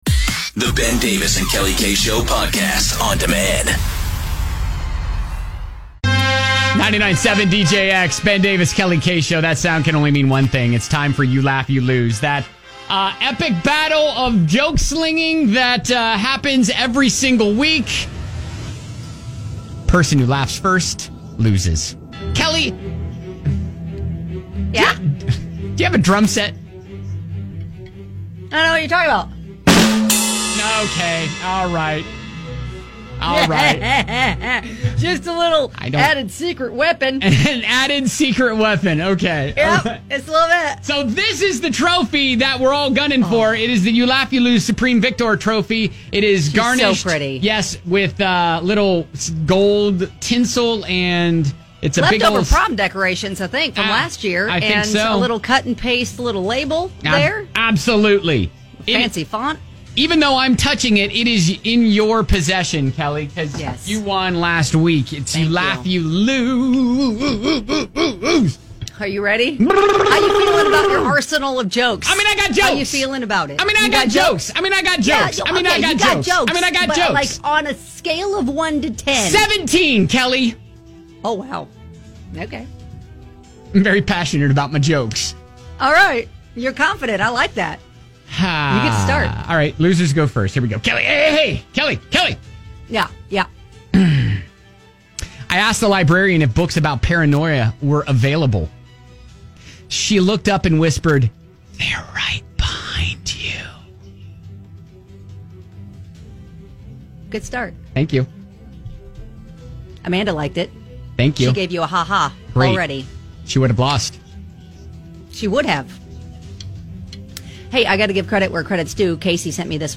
sling jokes back and forth until someone laughs